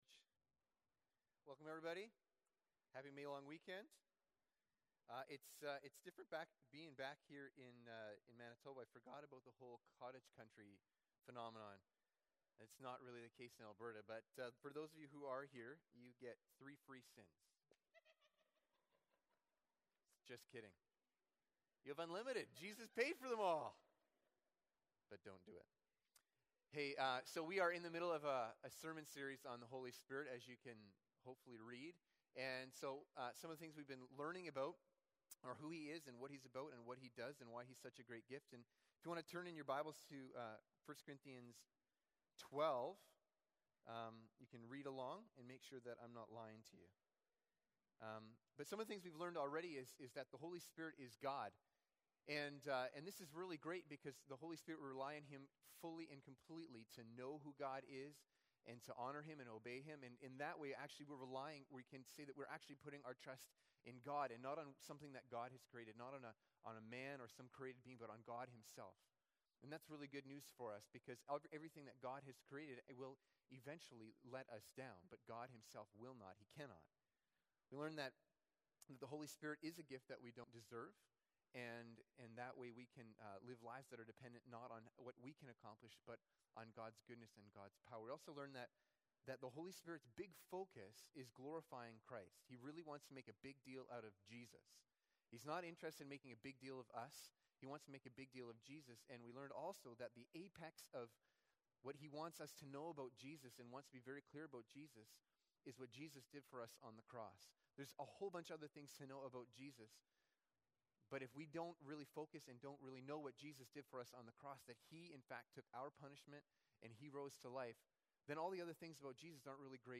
This is the final installment of our sermon series on the Holy Spirit.